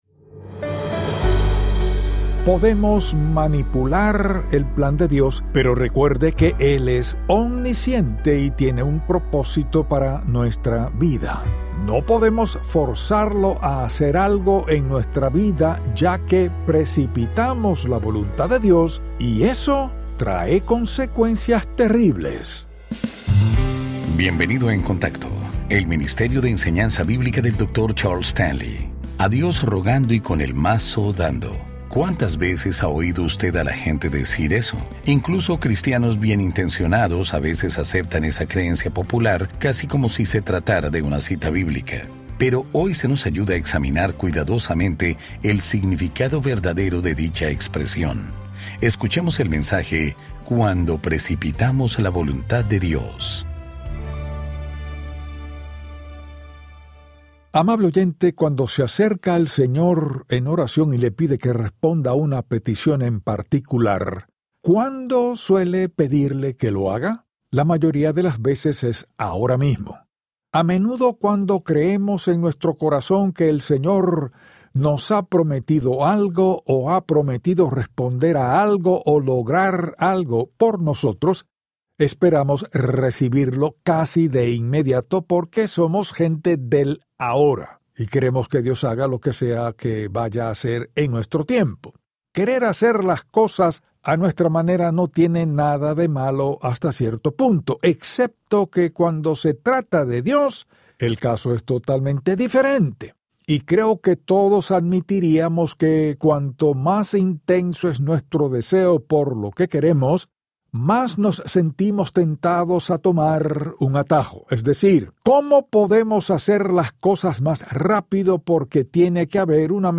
Dr. Charles Stanley y el programa diario de radio In Touch Ministries.